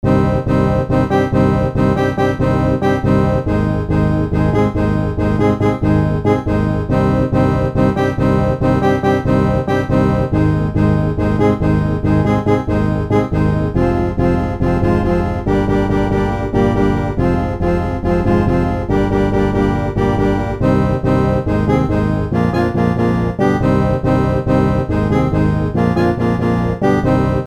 Apparently, the Wildflower Reed and Bass Organ can sound like a Steel Drum with the proper freak circumstances Edit: woah dude, it's even more prevalent if the Reed is switched to a Nylon Attachments Fate.mp3 Fate.mp3 644.1 KB · Views: 297 Foot.mp3 Foot.mp3 644.1 KB · Views: 275